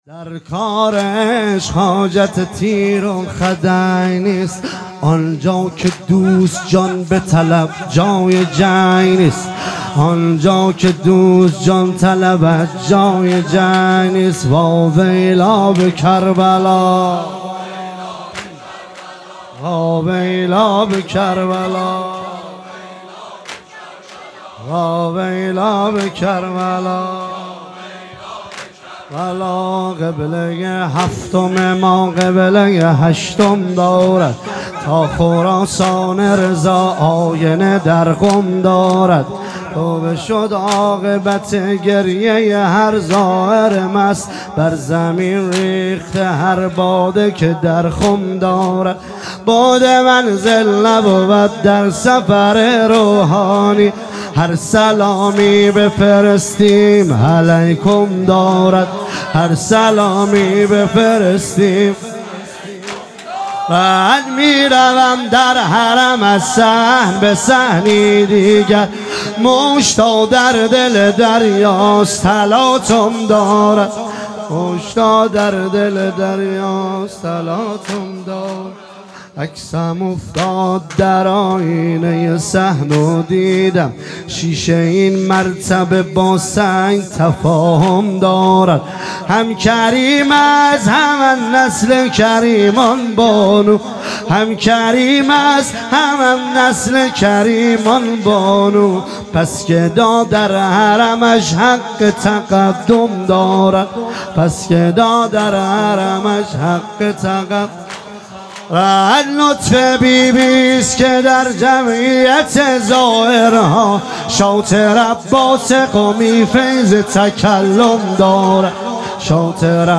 و جلسه ی هفتگی ۲۹ آذر ۱۳۹۷ هیئت حسین جان گرگان
واحد تند